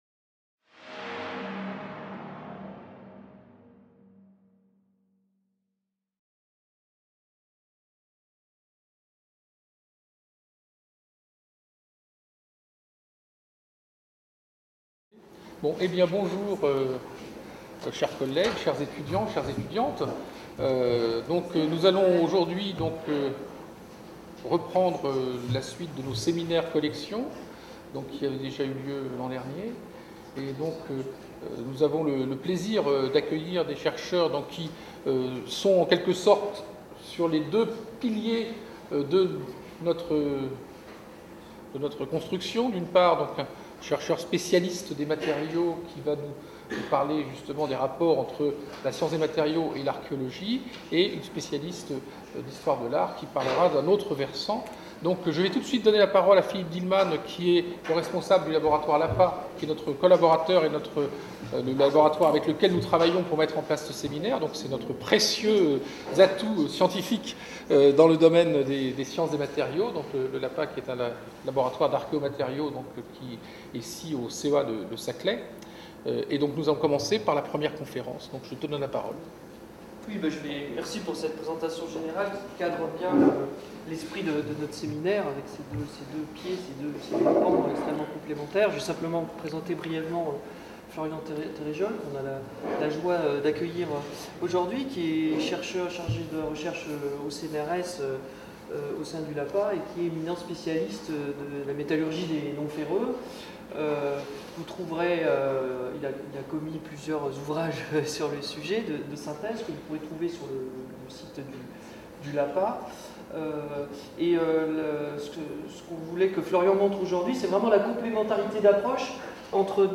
Deuxième séance du séminaire "Collections" 2015-2016.